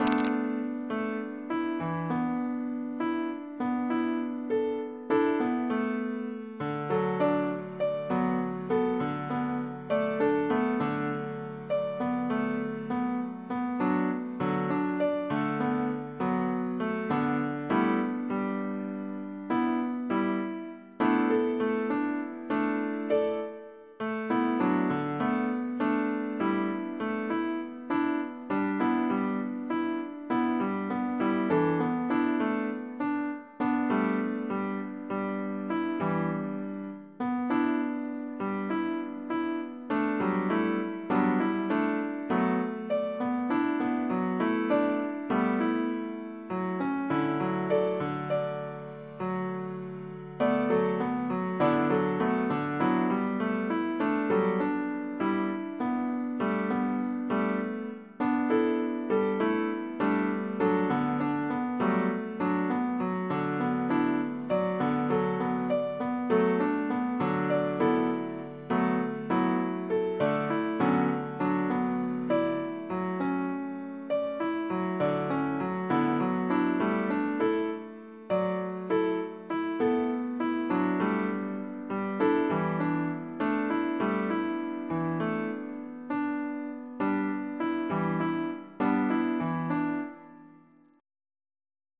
mp3) genearted by a network trained with the sqaure error cost function, following the same 300 gradient updates and 50 examples points leading the generation: